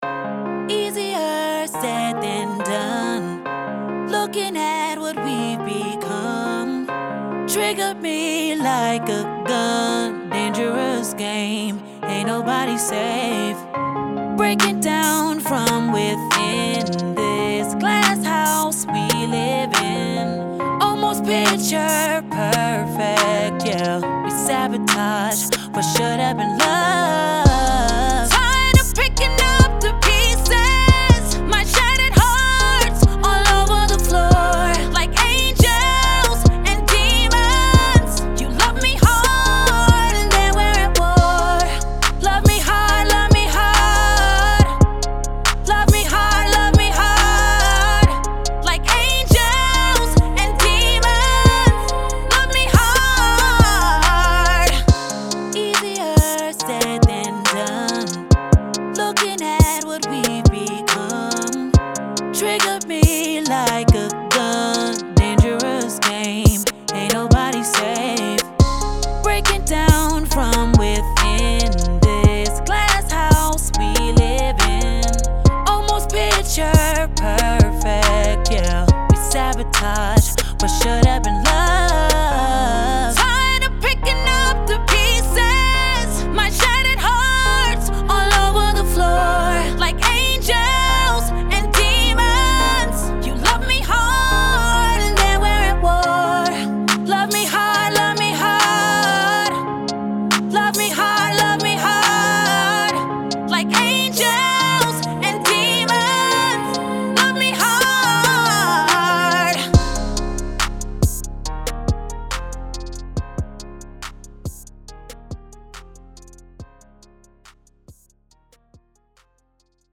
90s, R&B
F Minor